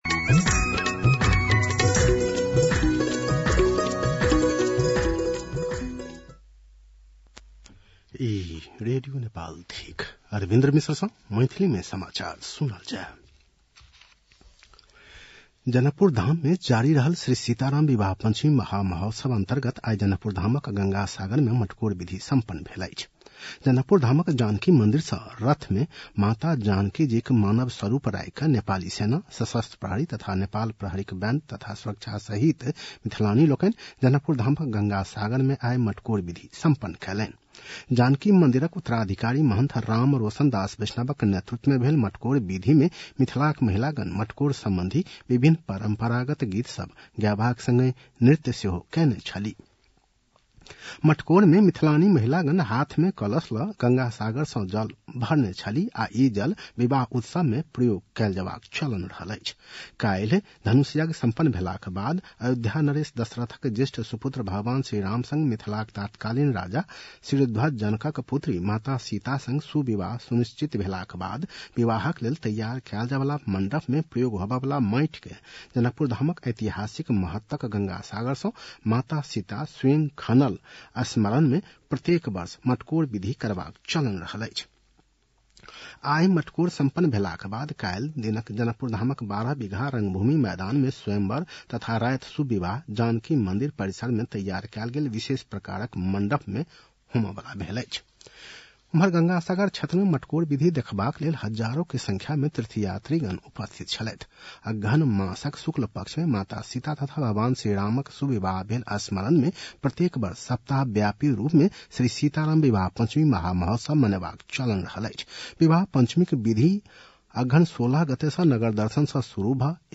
मैथिली भाषामा समाचार : २१ मंसिर , २०८१